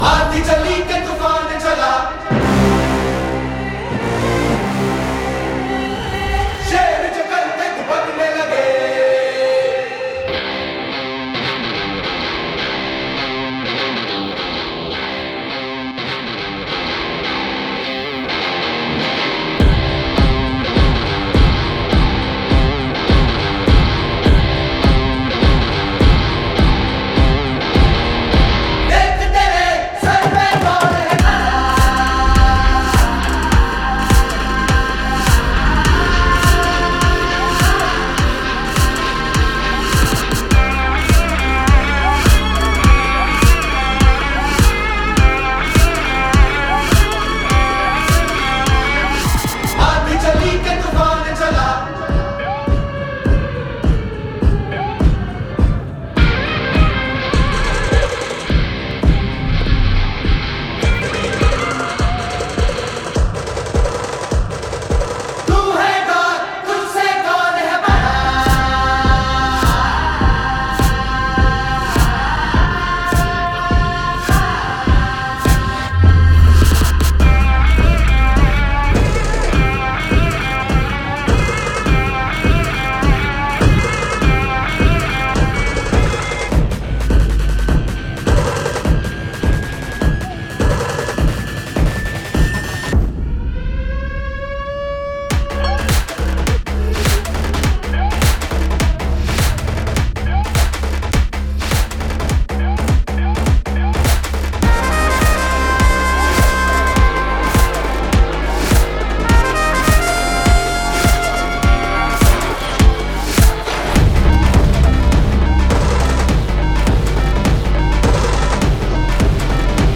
without dialogues and disturbances